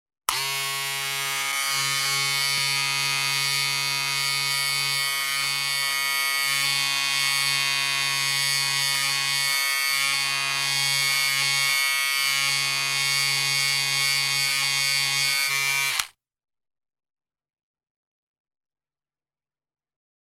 Звуки бритвы
Шум работающей электробритвы для волос